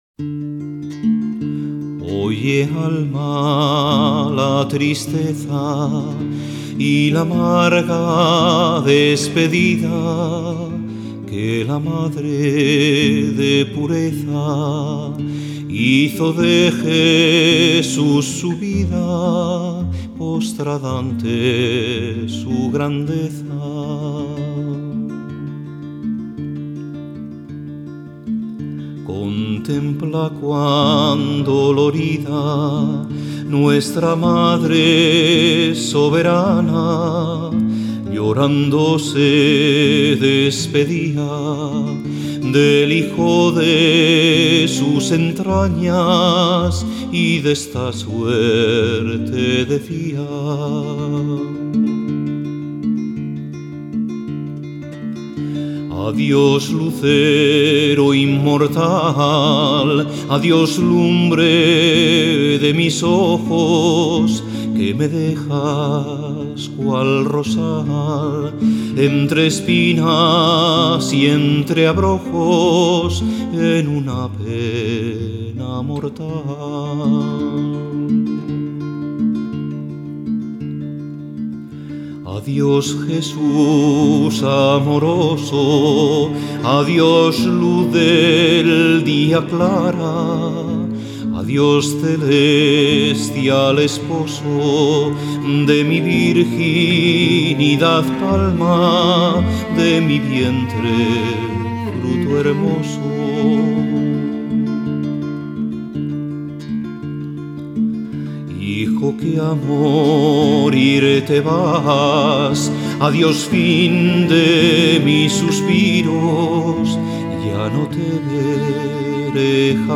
Voz y guitarra